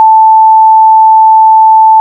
\(2\cdot 440\;\mathrm{Hz}=880\;\mathrm{Hz}\)
Audio abspielen Ton \(a^\prime\) + 1 Oktave = \(a^{\prime\prime}\)
Sinus-880Hz-2s.ogg